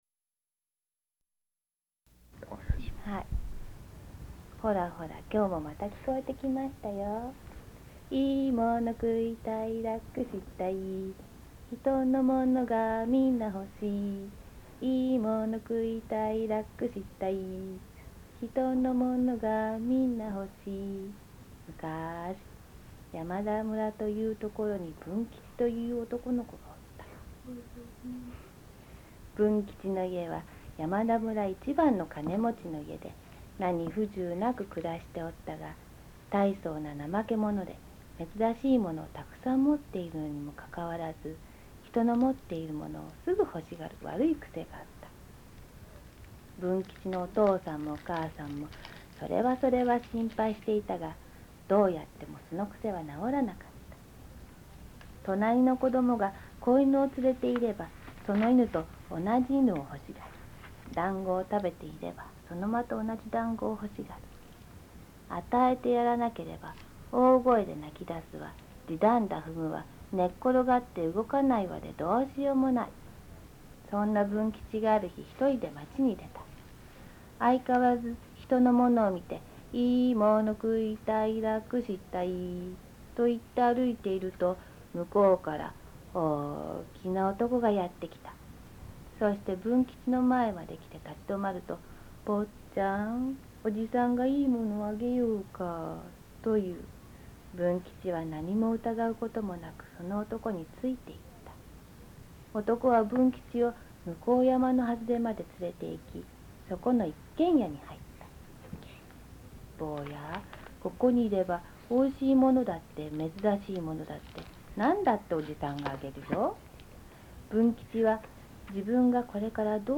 この話は、昭和32年（1957）生まれの松江市北堀町出身の女性からうかがったものである。